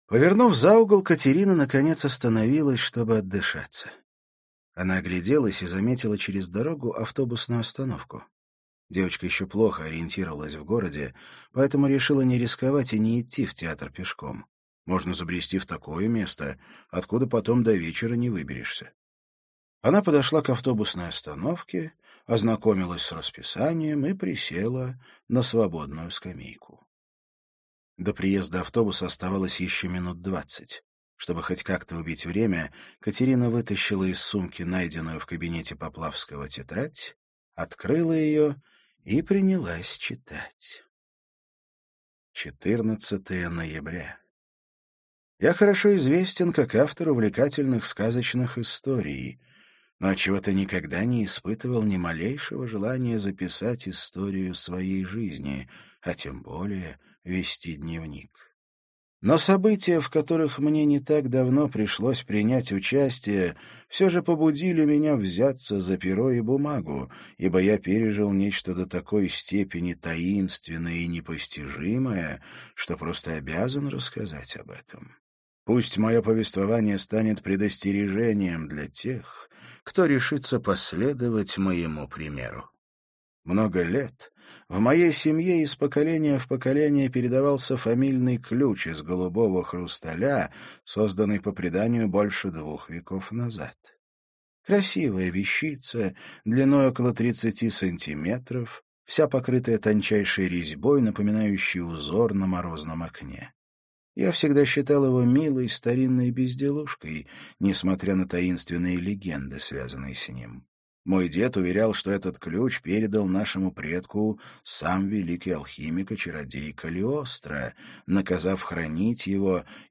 Аудиокнига Зерцалия. Иллюзион | Библиотека аудиокниг